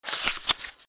/ M｜他分類 / L01 ｜小道具 / 文房具・工作道具
ページをめくる(本)